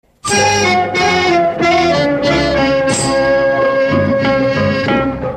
Nice Musical tune Zil sesi
Message Tones